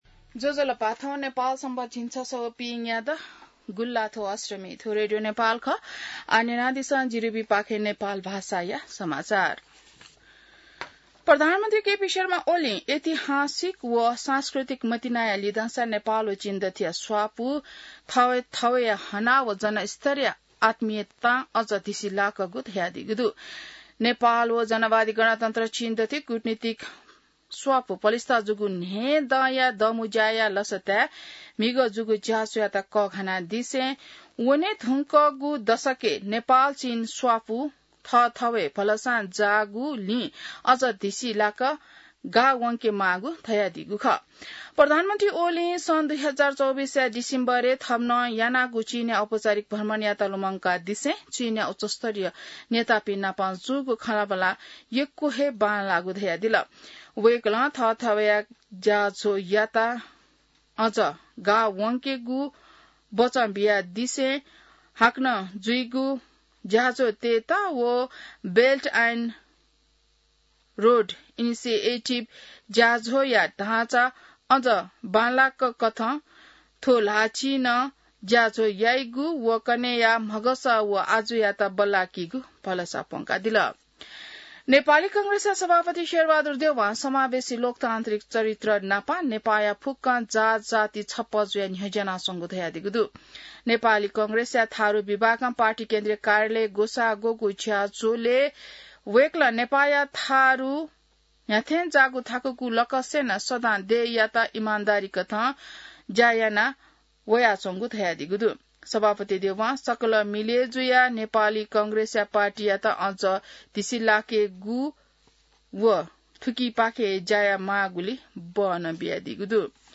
नेपाल भाषामा समाचार : १७ साउन , २०८२